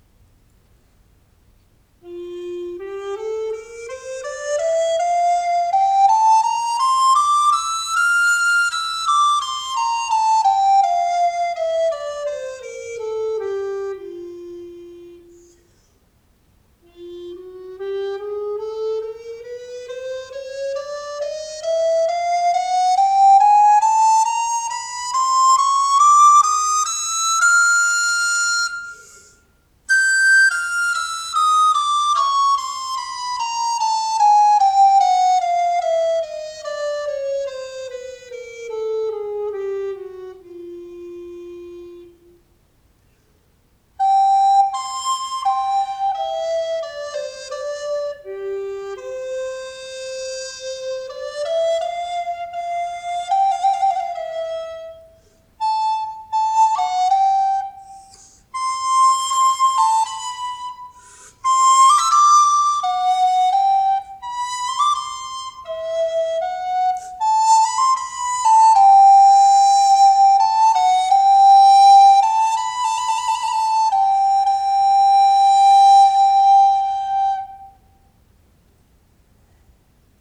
【材質】サクラ
チェリーは非常に軽い材のため、重量が手の負担になりません。一般にチェリー材の音色はエアリーで明るく温かみがあり、息をまとめてクリアな音を作ろうとしてもなおエアノイズが入ります。これはチェリーの持ち味ですが好みは分かれるかも。この個体は息の量はほどほど、軽くて吹きやすいです。音量はそれほど大きくありませんが、アンサンブルで特にゆったりとした曲を演奏する際に、バランスよく振る舞えそうです。